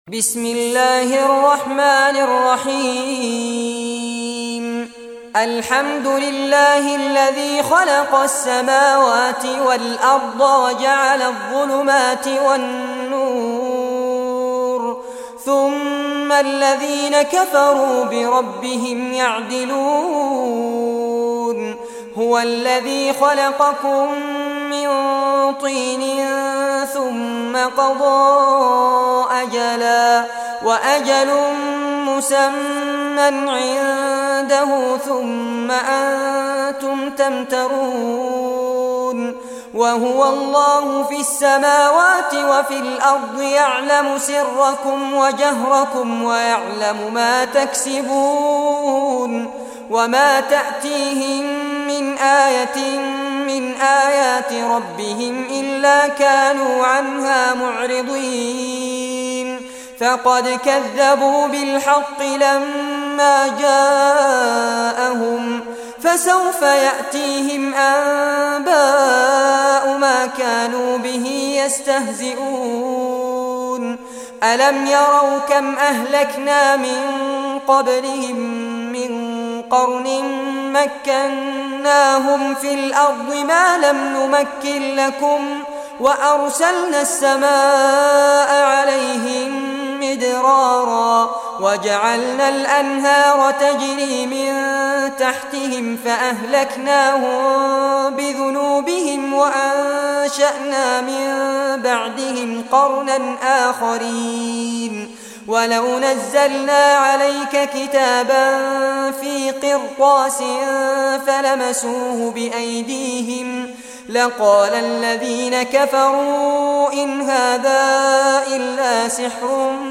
Surah Al-Anaam Recitation by Sheikh Fares Abbad
Surah Al-Anaam, listen or play online mp3 tilawat / recitation in Arabic in the beautiful voice of Sheikh Fares Abbad.